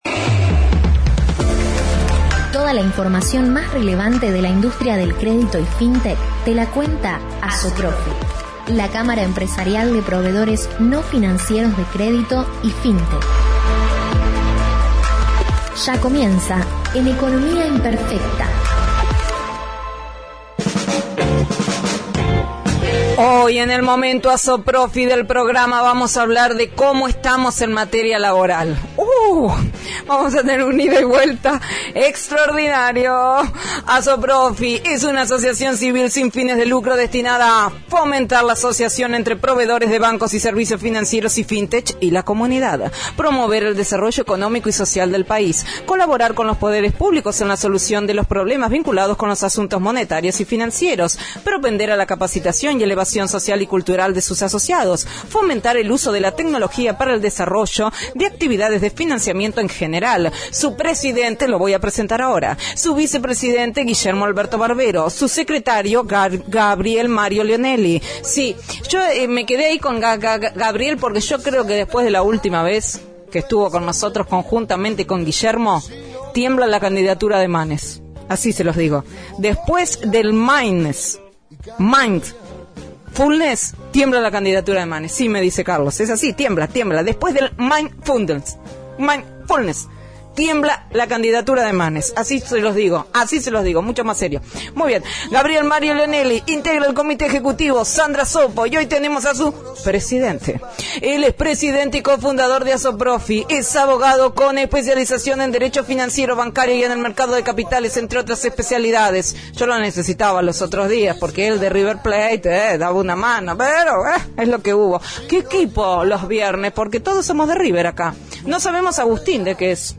ASOPROFI – COLUMNA RADIAL – RADIO AM 1420 Viernes 20/08/2021 – ¿Cómo estamos en materia laboral?